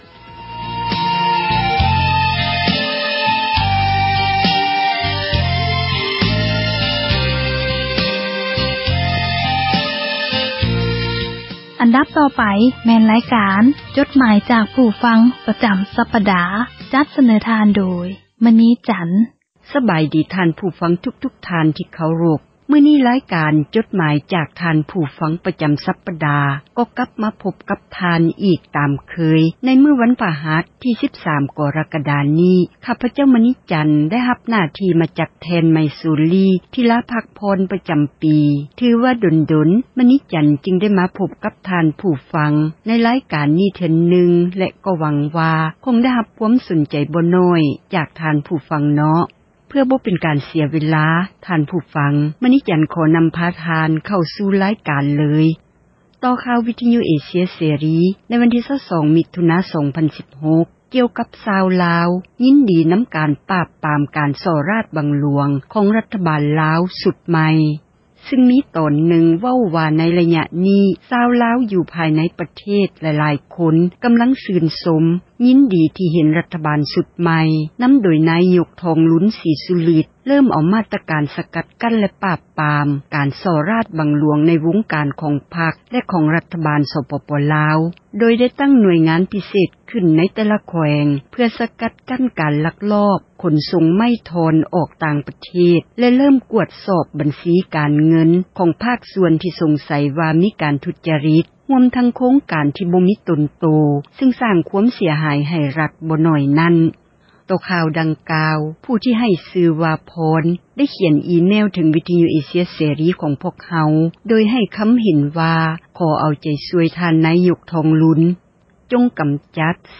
ຣາຍການ ອ່ານ ຈົດໝາຍ ຈາກ ຜູ້ຟັງ ປະຈຳ ສັປດາ ຈັດສເນີ ທ່ານ ຜູ້ຟັງ ໂດຍ